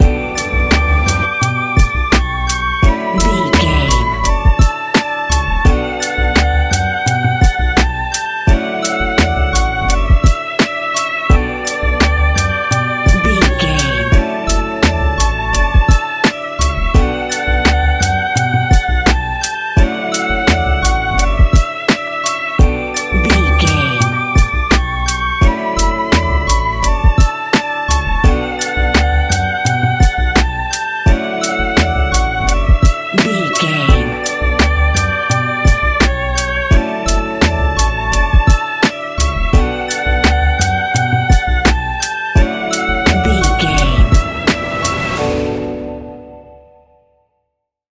Ionian/Major
D♭
laid back
Lounge
sparse
new age
chilled electronica
ambient
atmospheric
morphing